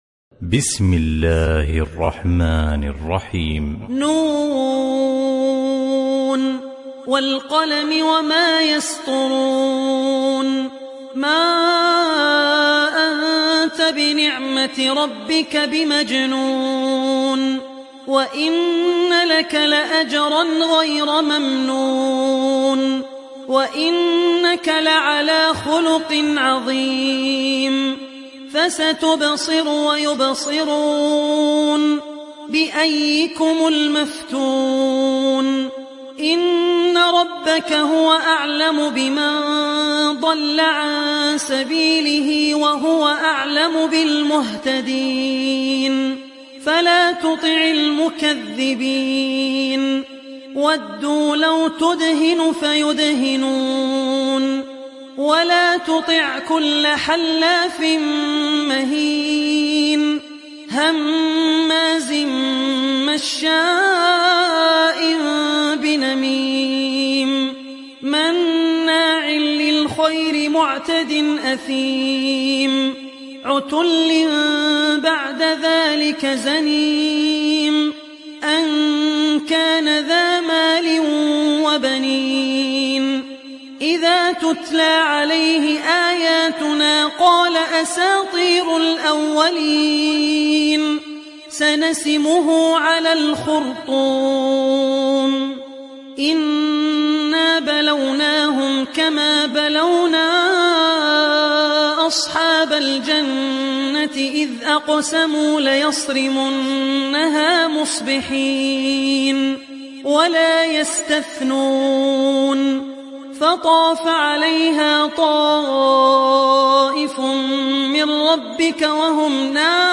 تحميل سورة القلم mp3 بصوت عبد الرحمن العوسي برواية حفص عن عاصم, تحميل استماع القرآن الكريم على الجوال mp3 كاملا بروابط مباشرة وسريعة